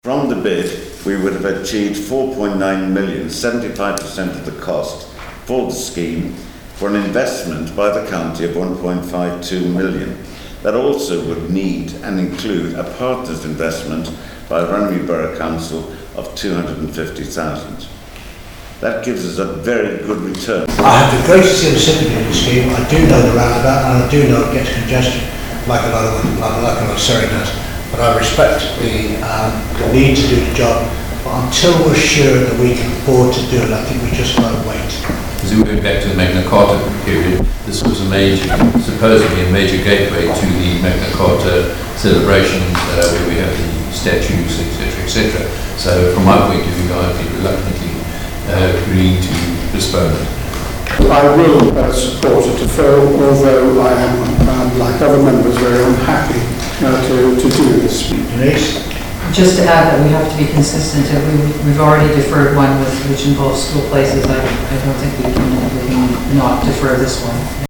Runnymede: John Furey, David Hodge, Mel Few, Peter Martin and Denise Le Gal in discussion.